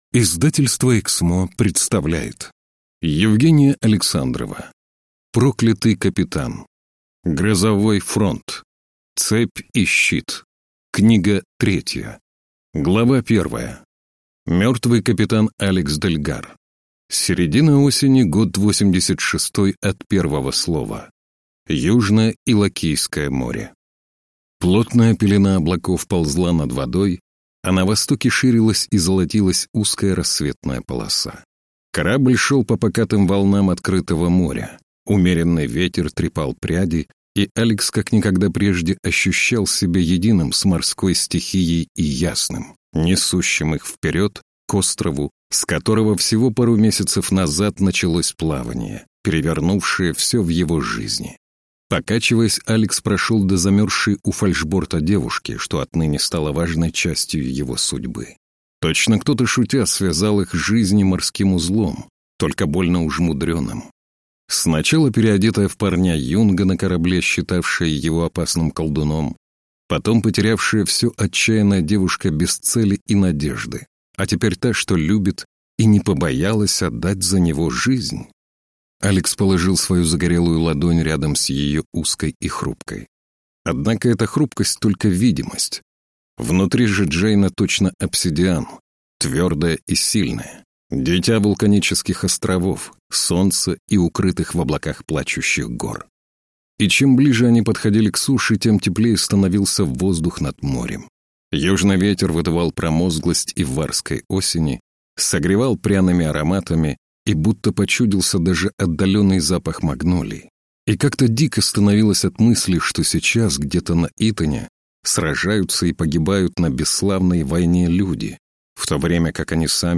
Аудиокнига Проклятый капитан. Грозовой фронт | Библиотека аудиокниг